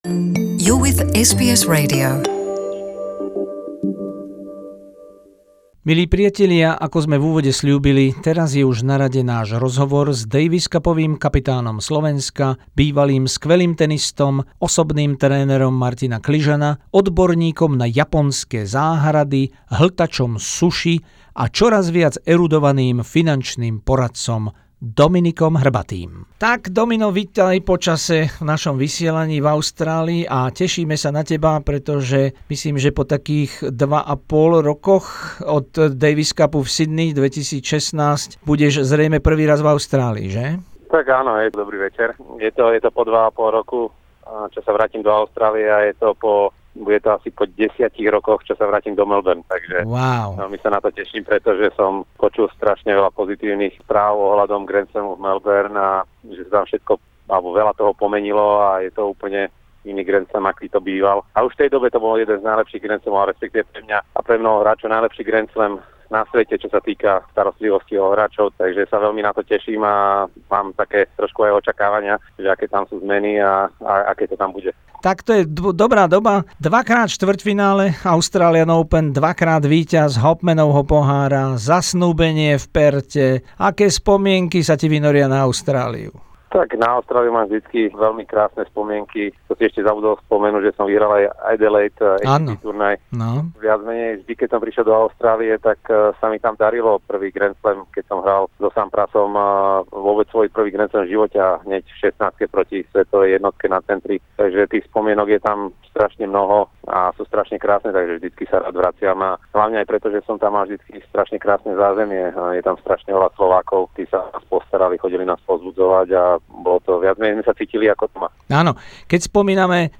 Rozhovor s daviscupovým kapitánom Slovenska, bývalým skvelým tenistom, osobným trénerom Martina Kližana, odborníkom na japonské záhrady, hltačom suši a čoraz viac erudovaným finančným poradcom Dominikom Hrbatým.